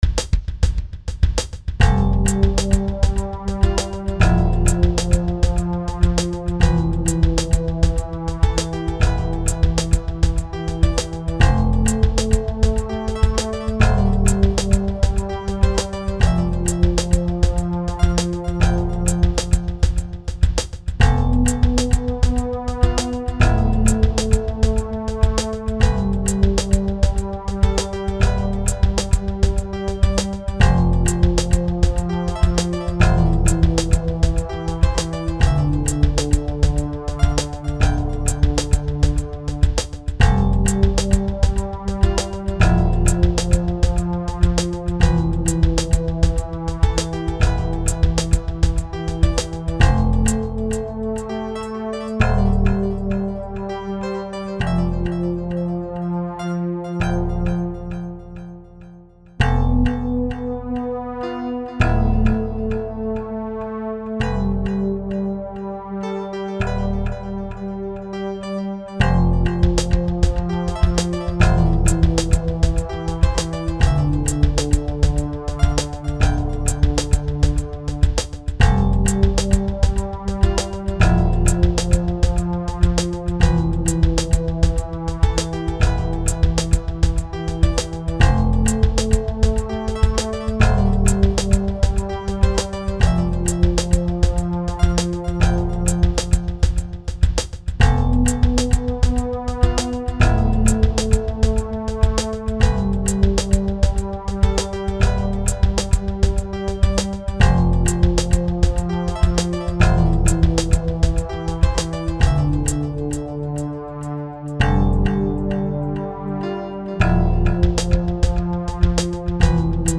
Below you should be able to listen to this horribly rainy track:
rainy_window.mp3